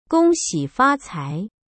HERE’S HOW TO SAY THE CNY GREETINGS IN MANDARN
gōngxǐ fācái